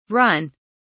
run kelimesinin anlamı, resimli anlatımı ve sesli okunuşu